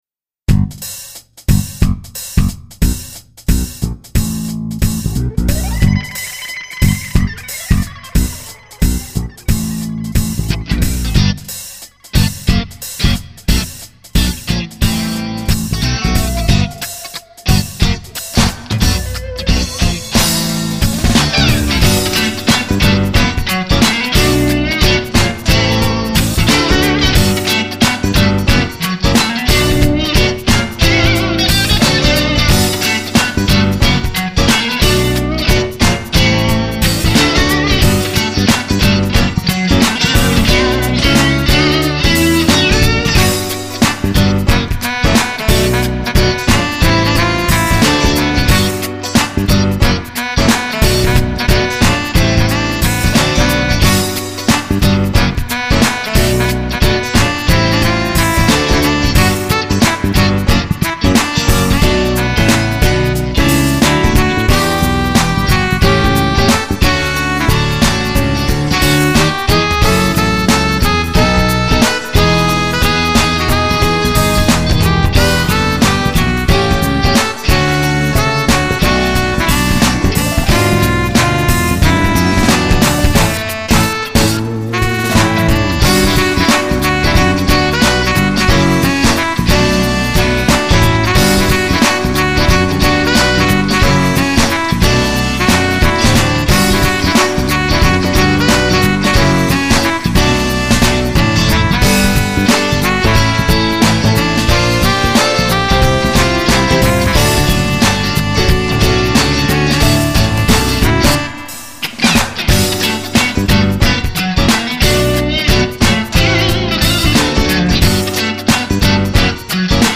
ややJazzyなＲ＆Ｂです。